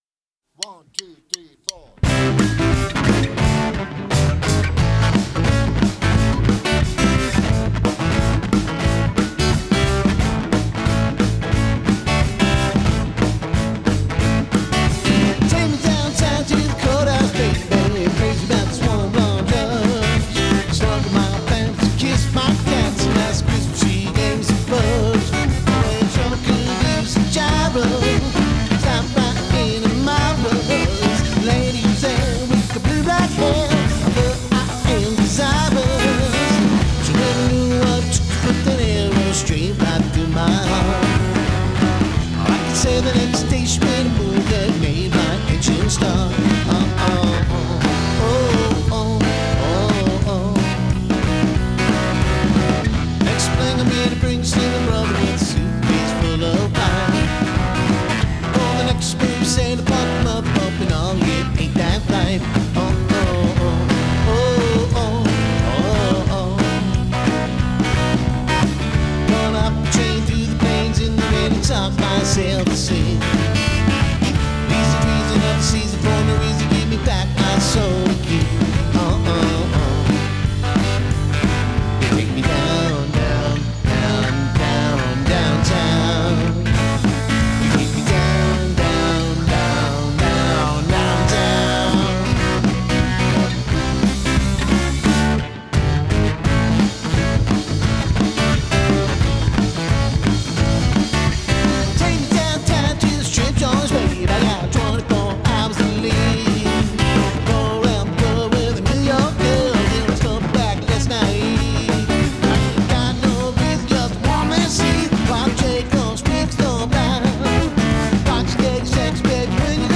Demo (live take July 2025)